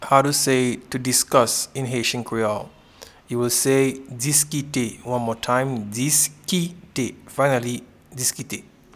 Pronunciation and Transcript:
to-Discuss-in-Haitian-Creole-Diskite.mp3